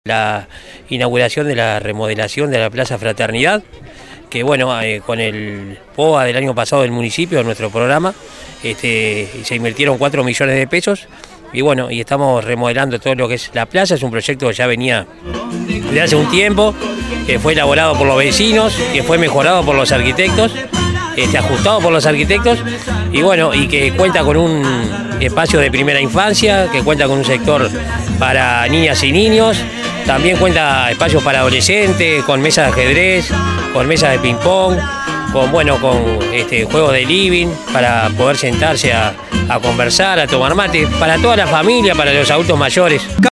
El sábado 13 de agosto, vecinas y vecinos del Municipio de Nicolich disfrutaron de una tarde de actividades recreativas y culturales en el marco de la inauguración de las obras de acondicionamiento de la plaza Fraternidad.
liber_moreno_alcalde_nicolich.mp3